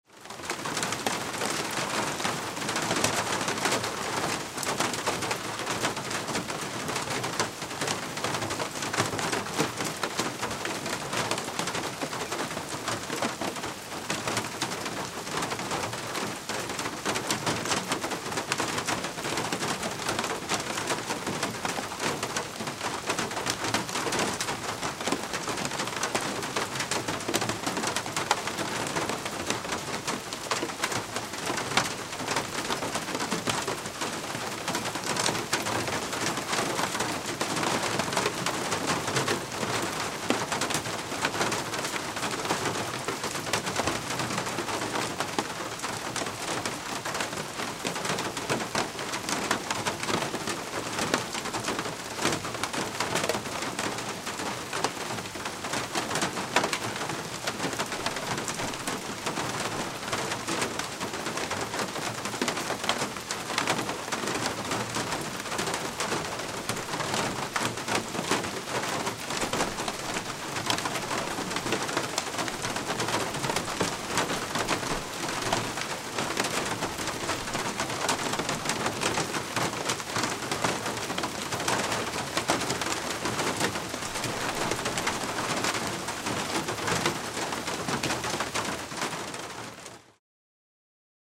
Звуки погоды
Дождь стучит по крыше